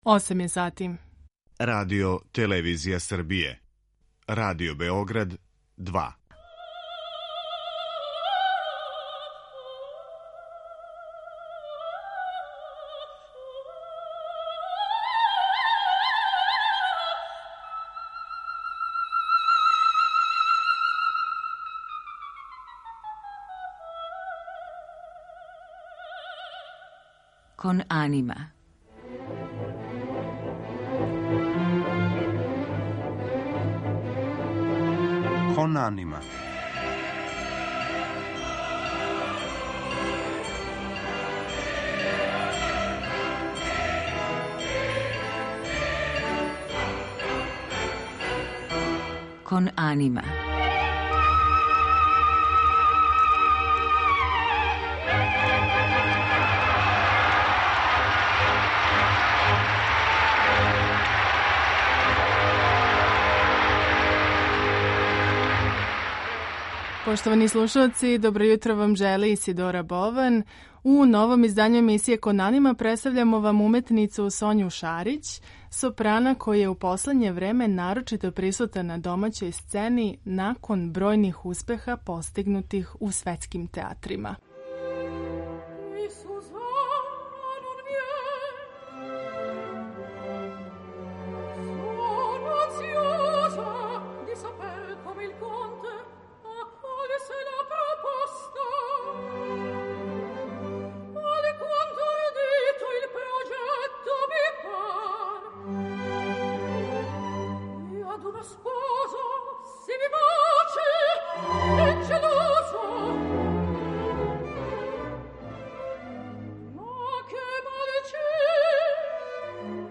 У данашњој емисији имаћете прилике да чујете снимке забележене у Атини и Будимпешти, као и одломак Вагнеровог Прстена Нибелунга који је посебно снимљен за радио у Паризу.